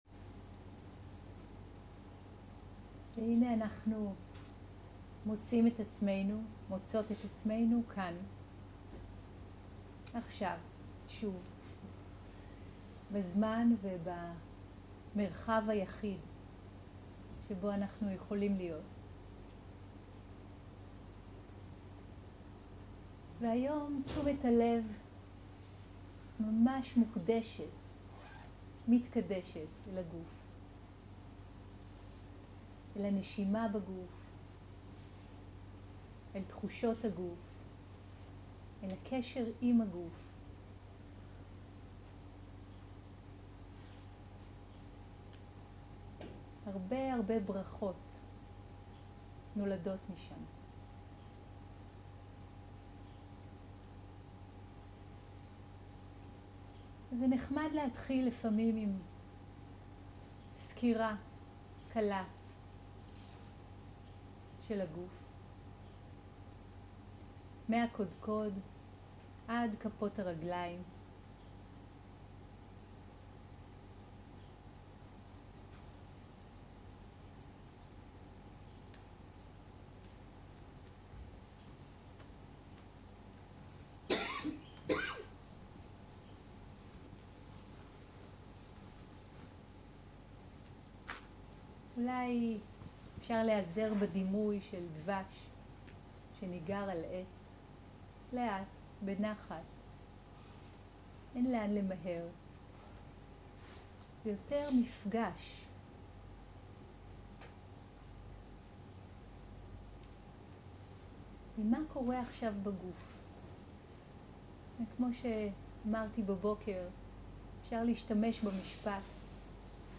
מדיטציה מונחית-תשומת לב לגוף
סוג ההקלטה: מדיטציה מונחית
איכות ההקלטה: איכות גבוהה